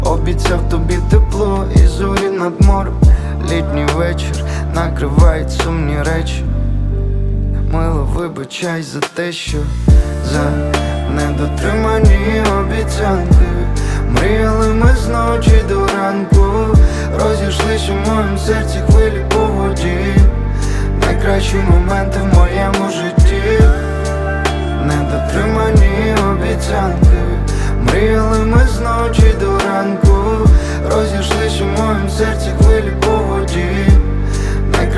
Жанр: Рэп и хип-хоп / Украинские